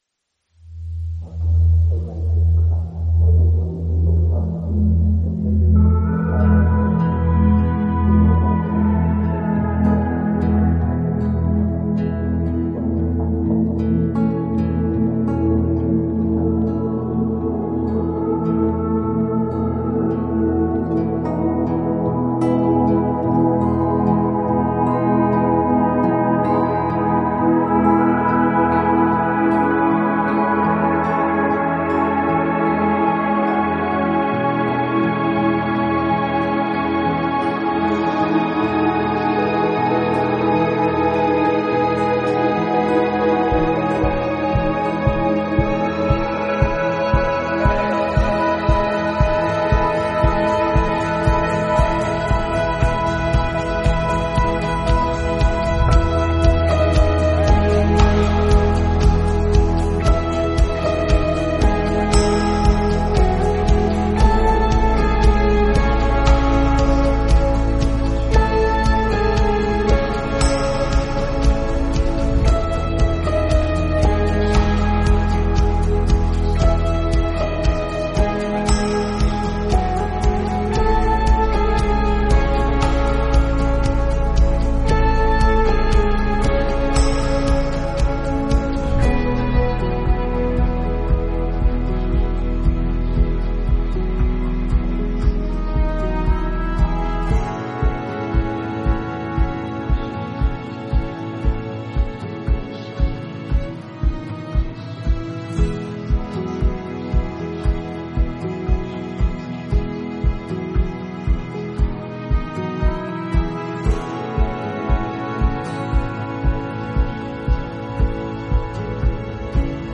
” you’ll hear those melodies return and find fulfillment.
instrumental album